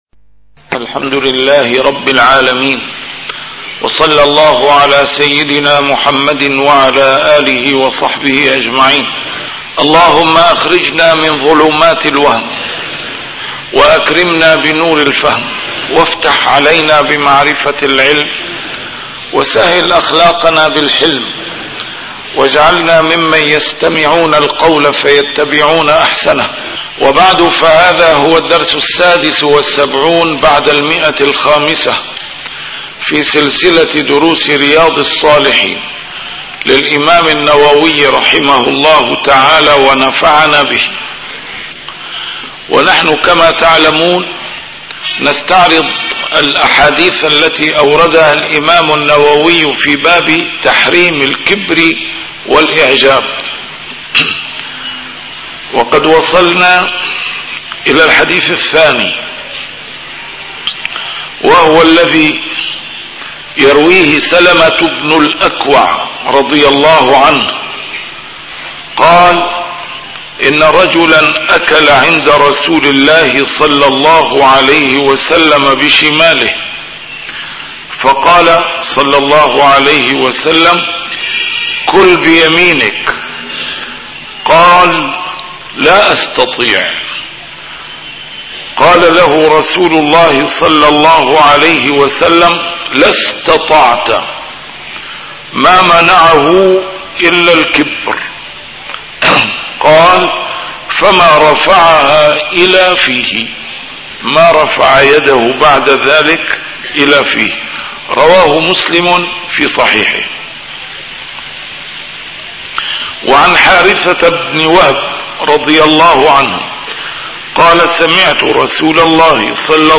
A MARTYR SCHOLAR: IMAM MUHAMMAD SAEED RAMADAN AL-BOUTI - الدروس العلمية - شرح كتاب رياض الصالحين - 576- شرح رياض الصالحين: تحريم الكبر والإعجاب